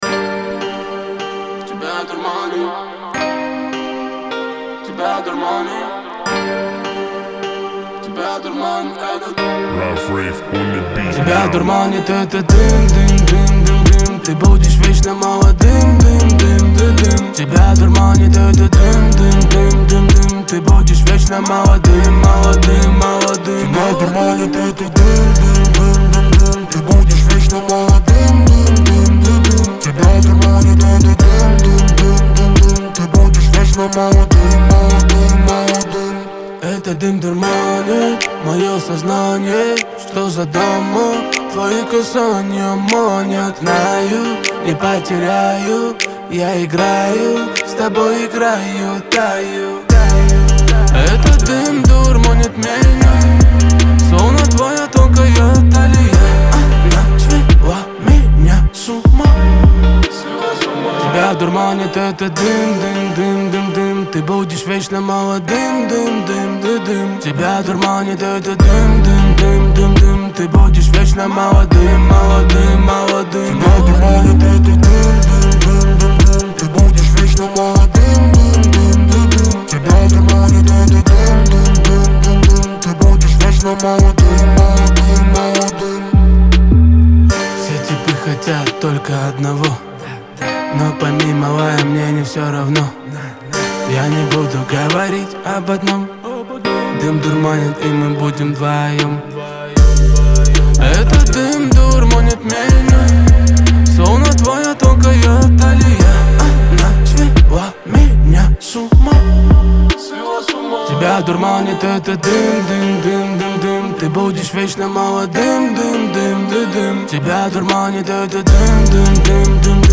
выполненный в жанре альтернативного рока с элементами инди.